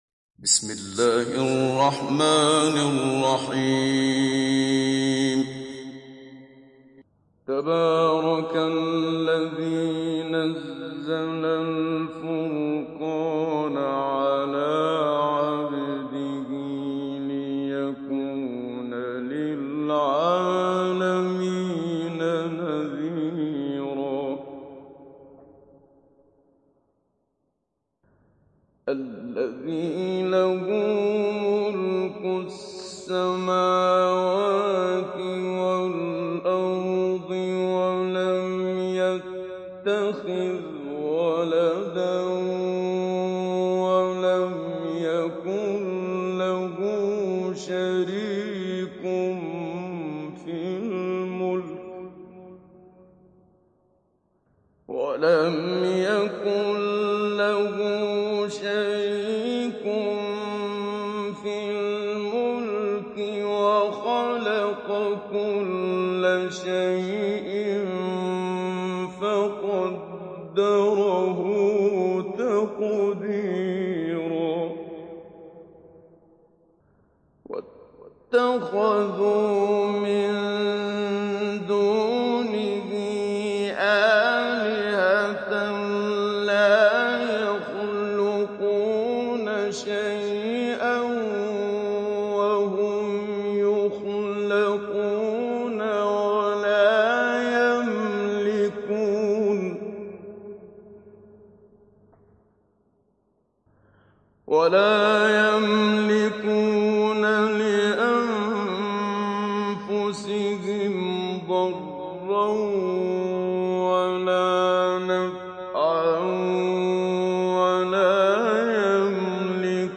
دانلود سوره الفرقان محمد صديق المنشاوي مجود